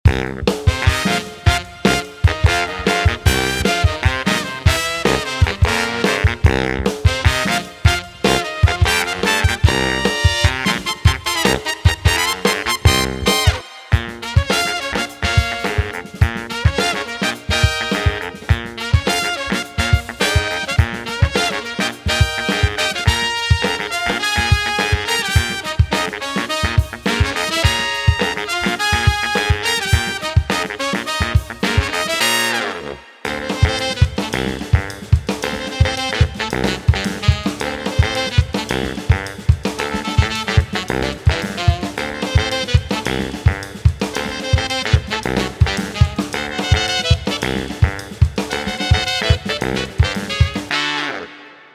58 Ensemble Horn Loops: Immerse your listeners in the lush, layered textures of a full horn section, ideal for creating dramatic and impactful arrangements.
26 Alto Sax Loops: Add smooth, sultry tones to your compositions with these alto sax loops, perfect for crafting warm, melodic layers and soulful harmonies.
27 Baritone Sax Loops: Bring depth and resonance to your tracks with the powerful, grounding sound of baritone sax loops, adding weight and character to your music.
41 Sax Solo Loops: Capture the essence of expressive, captivating solos with these sax solo loops, perfect for creating memorable lead lines that stand out in any mix.
26 Tenor Sax Loops: Versatile and dynamic, the tenor sax loops provide everything from soulful melodies to powerful riffs, enhancing the versatility of your productions.
19 Trumpet Loops: Bright and bold, these trumpet loops deliver crisp, impactful stabs and melodic accents that cut through the mix with precision and clarity.